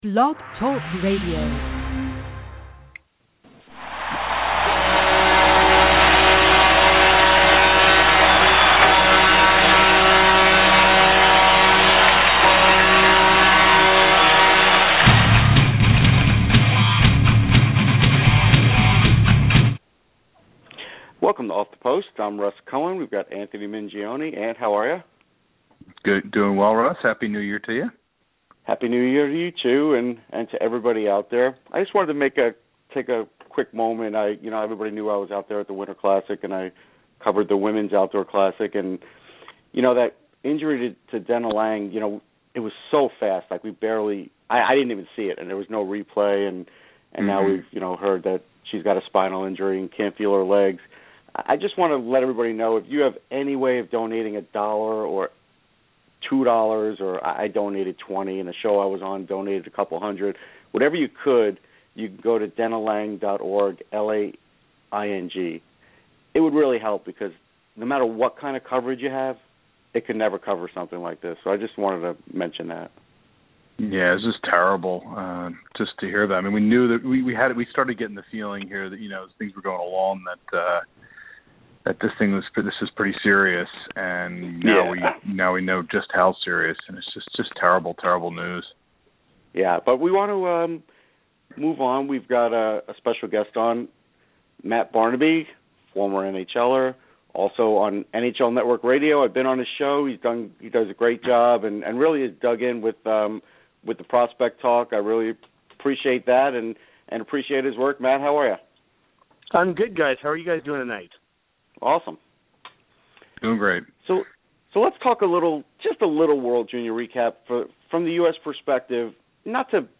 NHL Network Radio's Matthew Barnaby will come on the show to talk about the Word Junior Championships and more.